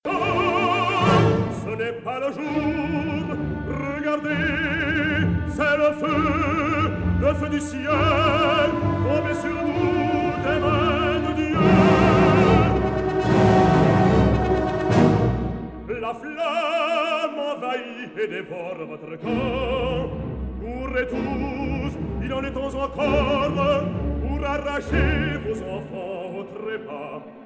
soprano
tenor
baritone
bass
Chorus and orchestra of the